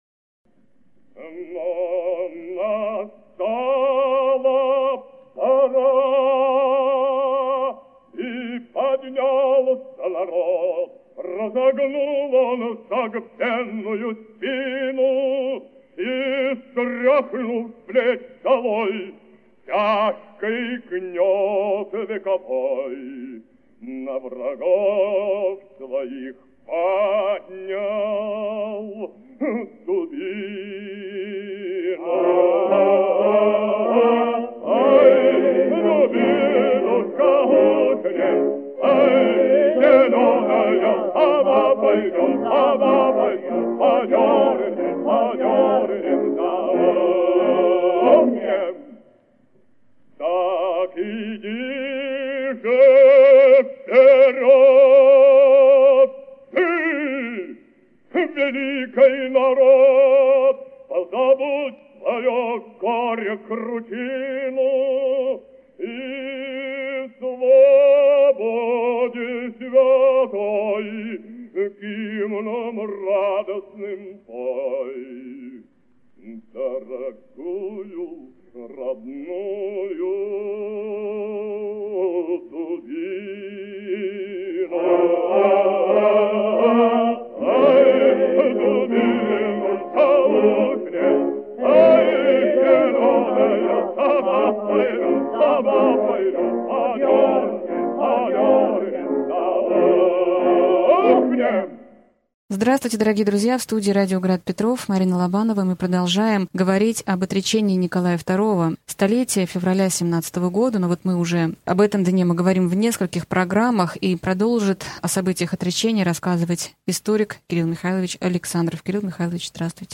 Аудиокнига Февральская революция и отречение Николая II. Лекция 31 | Библиотека аудиокниг